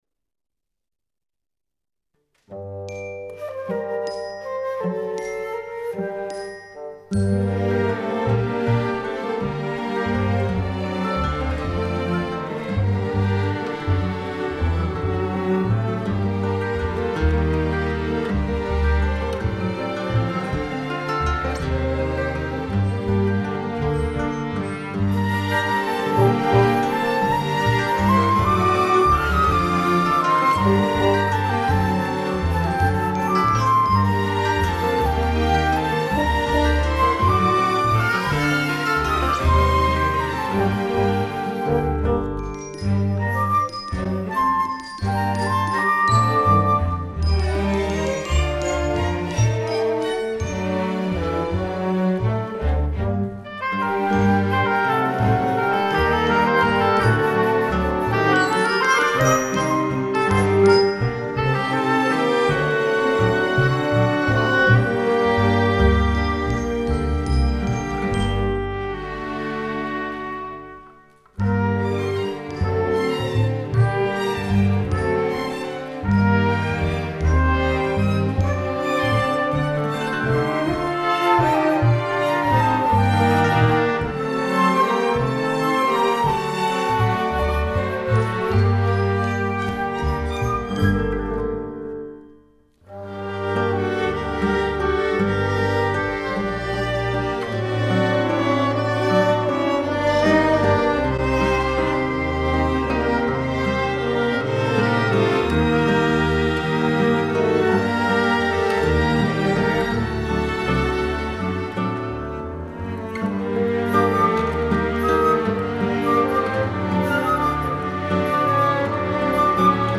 Επτά μουσικά θέματα ενορχηστρωμένα από τον συνθέτη
όπως και ελεύθερα  μουσικά θέματα κινηματογραφικής χροιάς.